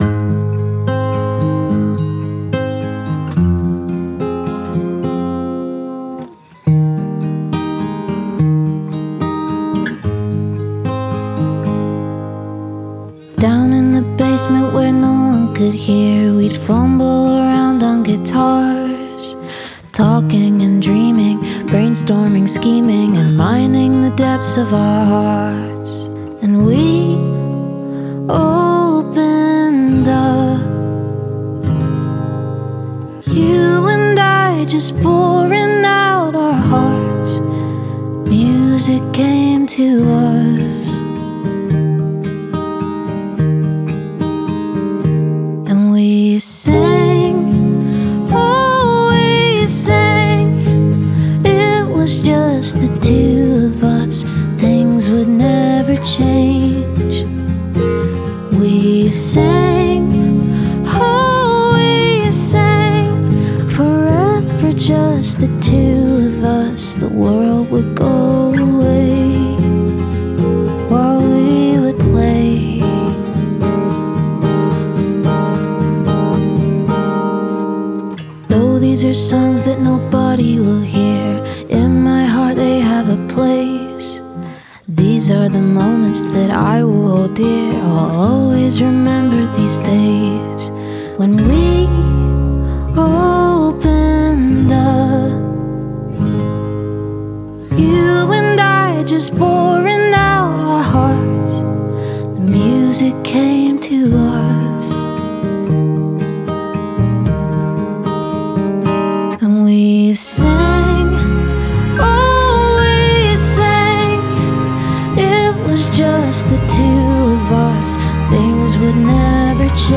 professionally produced
beautiful vocals